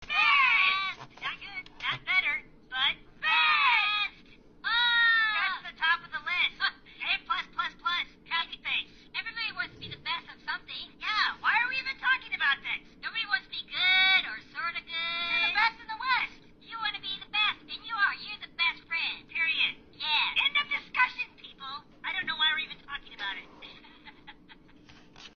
You Are The Best! is a hoops&yoyo greeting card with lights and sound made for friendship.
Card sound